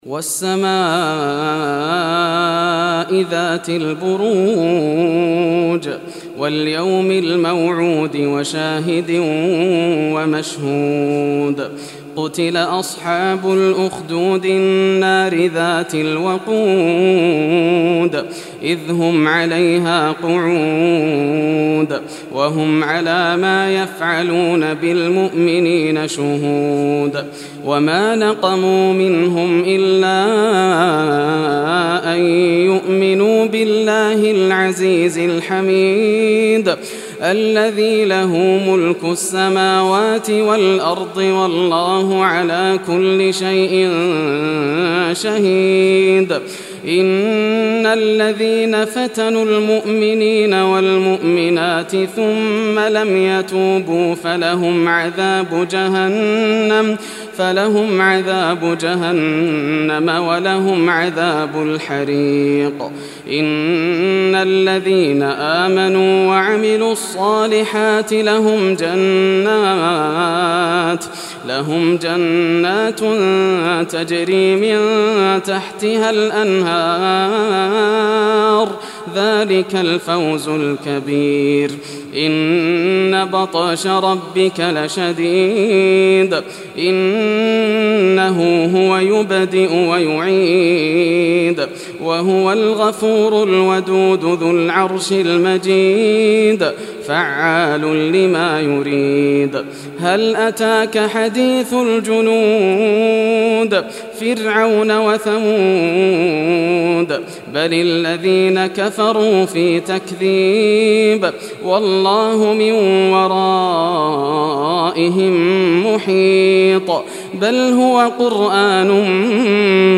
Surah Al-Buruj Recitation by Yasser al Dosari
Surah Al-Buruj, listen or play online mp3 tilawat / recitation in Arabic in the beautiful voice of Sheikh Yasser al Dosari.